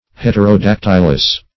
heterodactylous.mp3